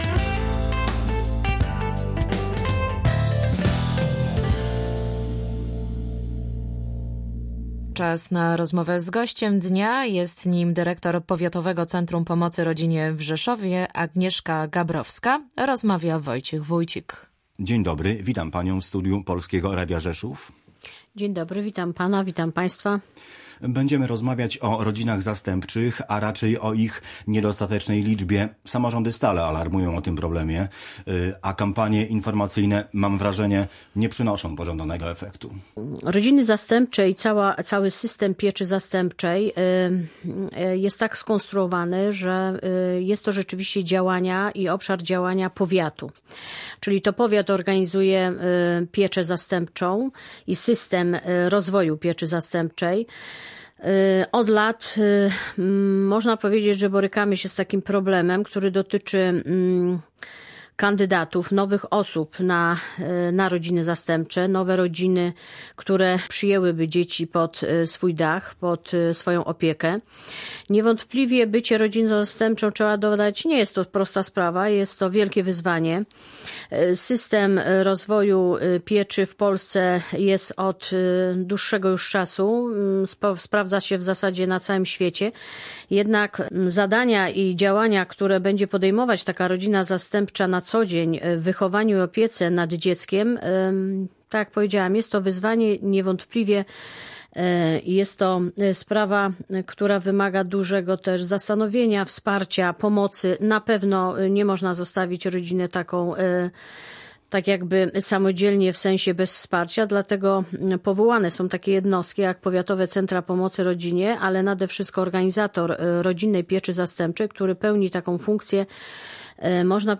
Więcej na ten temat w rozmowie